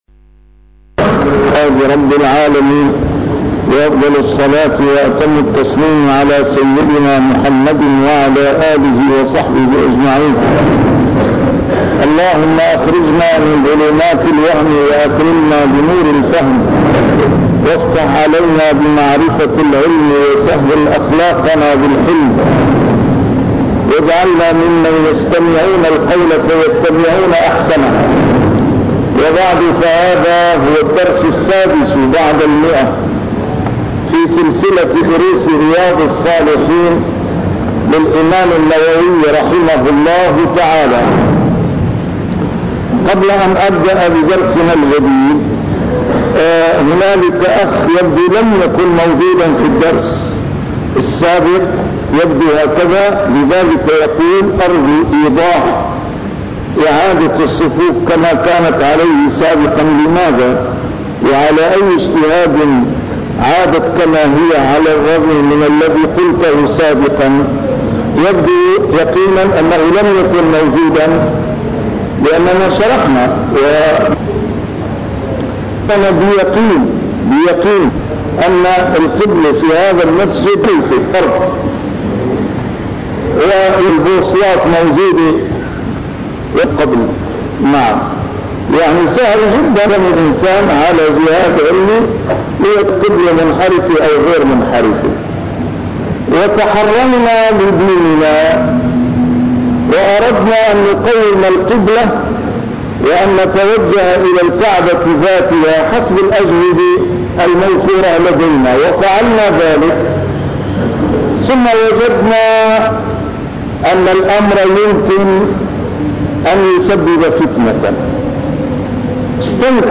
A MARTYR SCHOLAR: IMAM MUHAMMAD SAEED RAMADAN AL-BOUTI - الدروس العلمية - شرح كتاب رياض الصالحين - 106- شرح رياض الصالحين: المبادرة إلى الخيرات